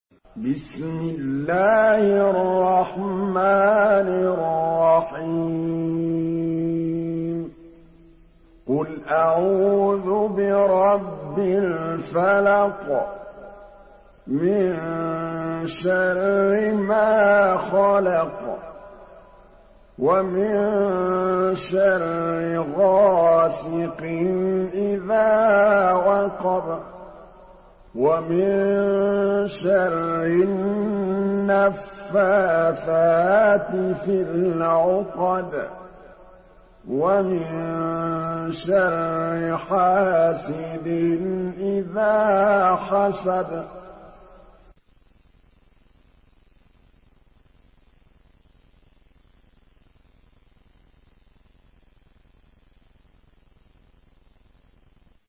سورة الفلق MP3 بصوت محمد محمود الطبلاوي برواية حفص
مرتل